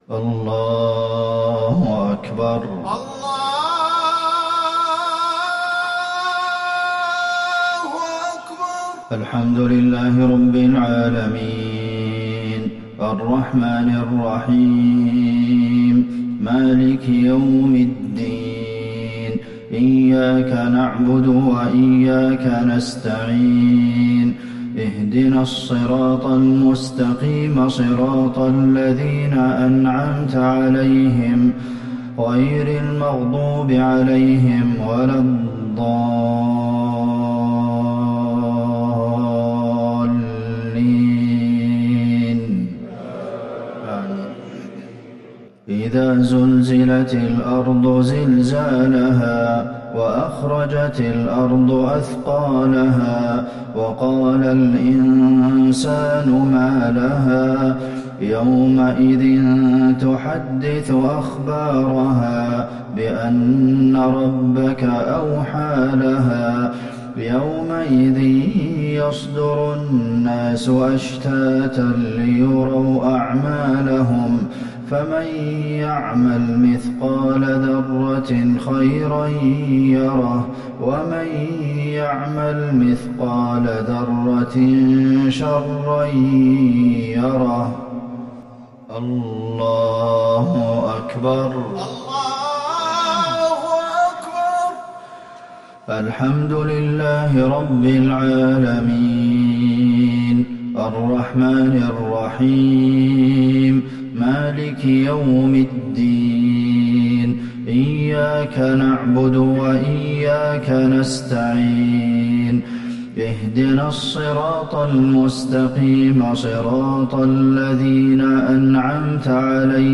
صلاة المغرب للشيخ عبدالمحسن القاسم 10 رجب 1441 هـ
تِلَاوَات الْحَرَمَيْن .